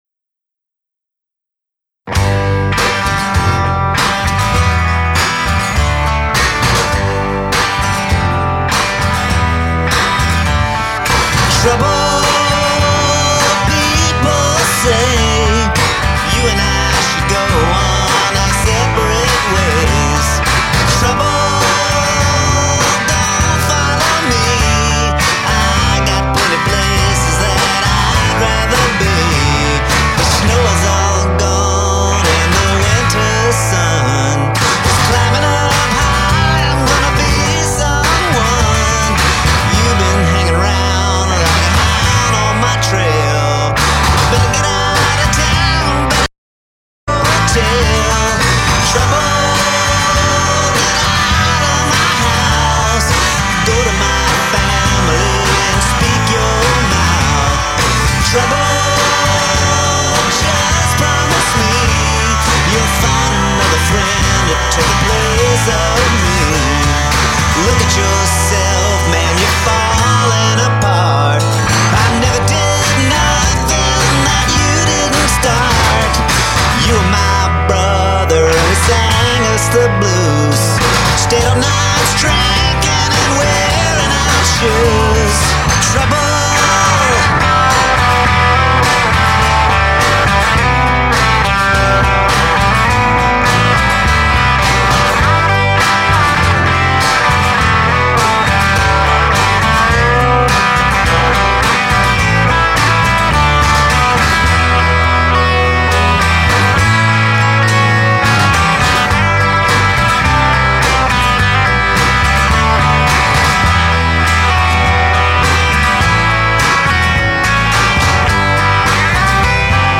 I recorded this at home on an 8-track cassette.
an upbeat, optimistic number reminiscent of "Rocking Chair"